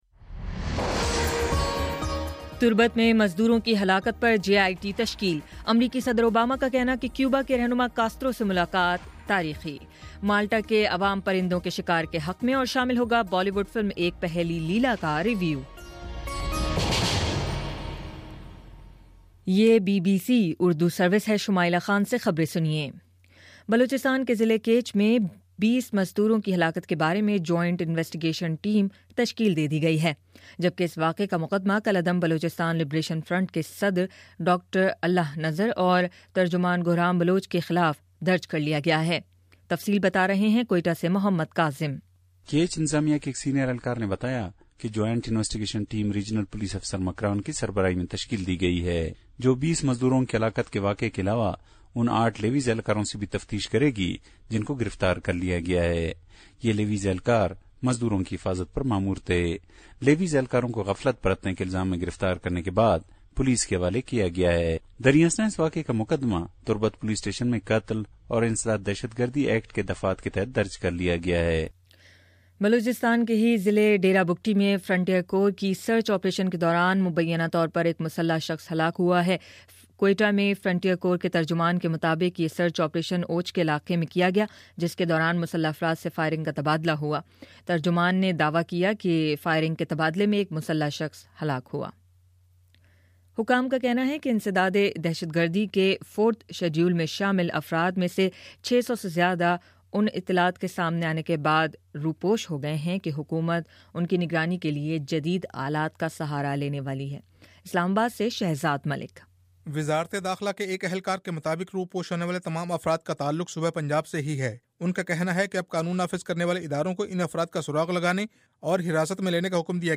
اپریل12 : شام پانچ بجے کا نیوز بُلیٹن